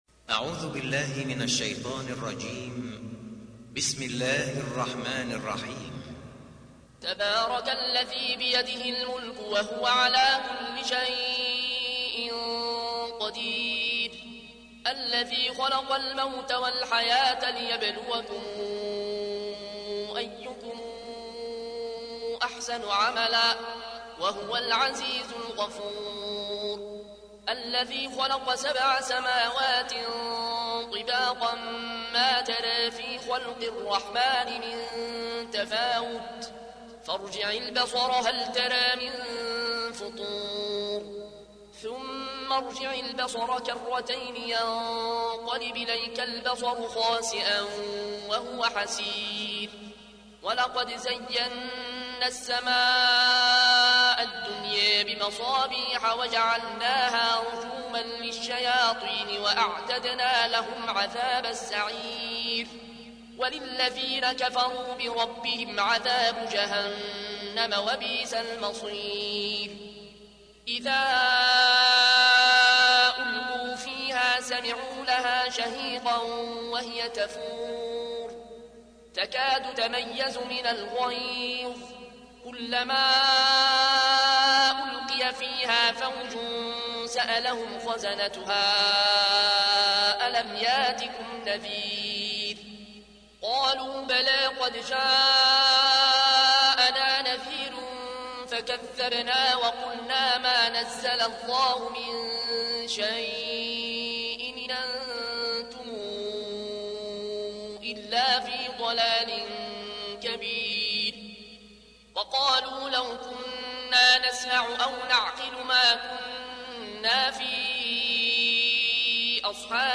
تحميل : 67. سورة الملك / القارئ العيون الكوشي / القرآن الكريم / موقع يا حسين